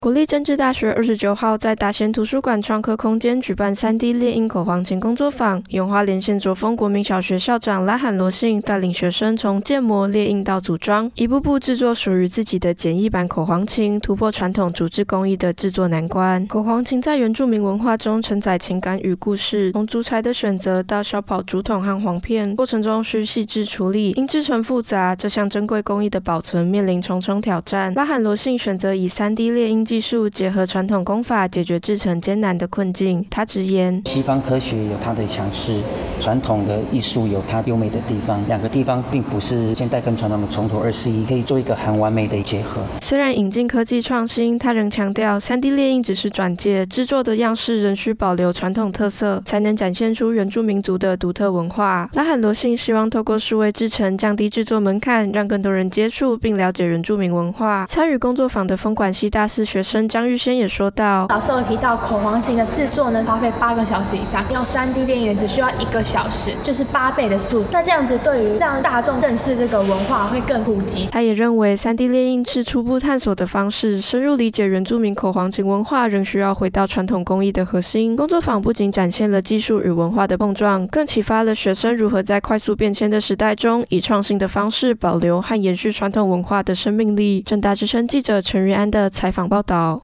政大之聲實習廣播電台-大台北重點新聞